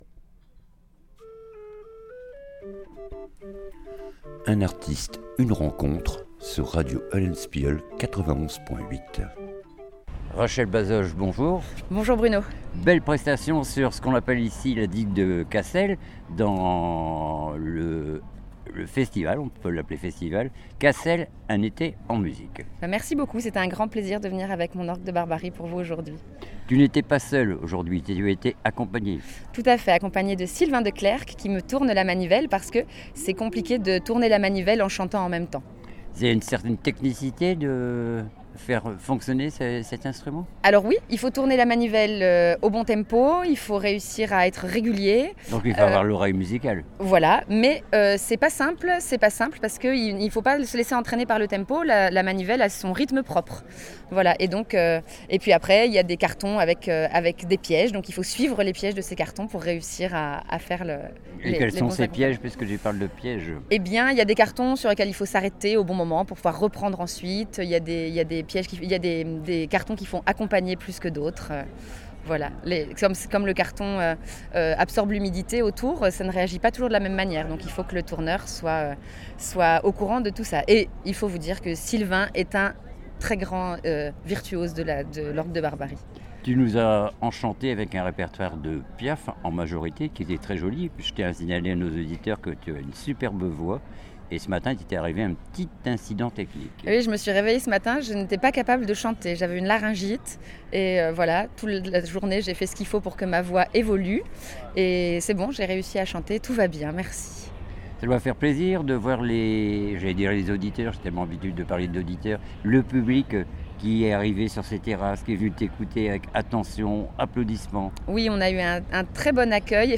DANS LA CADRE DE CASSEL UN ETE EN MUSIQUE